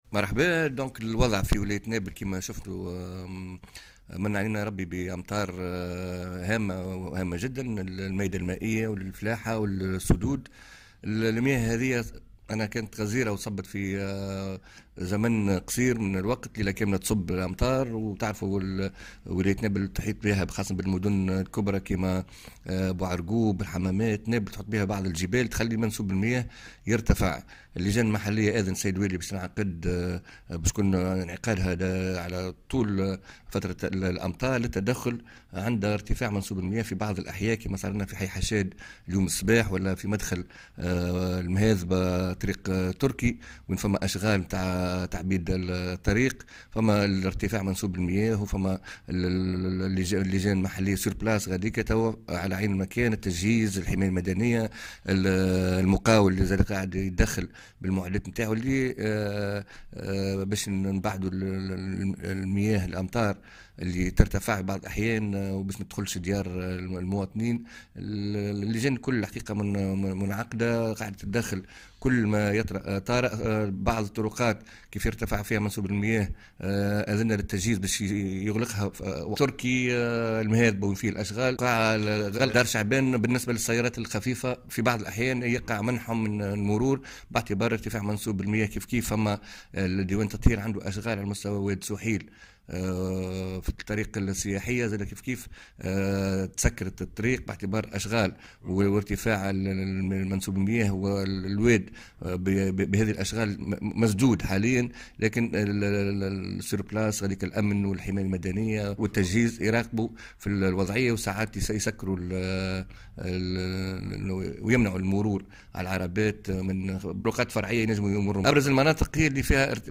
وأضاف في تصريح لمراسلة "الجوهرة أف أم" أنه تقرر انعقاد اللجان المحلية على امتداد هطول المطار بعد تسجيل ارتفاع منسوب المياه ببعض المناطق من الجهة، وسيم العمل على تفادي تسربها إلى المنازل.